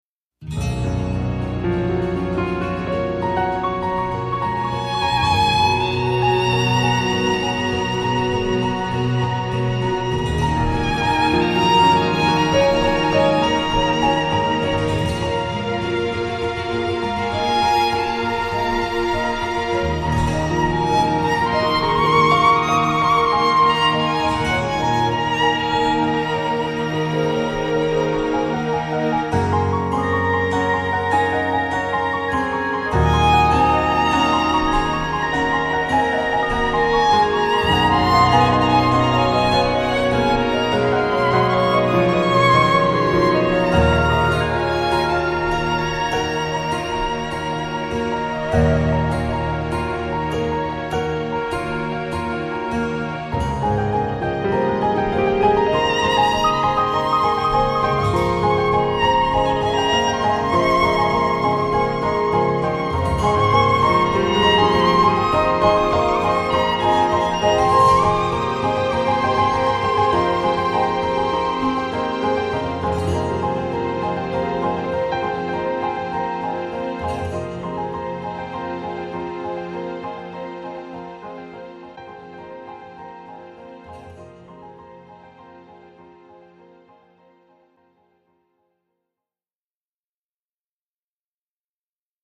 主要演奏器乐： 钢琴
乐风： 跨界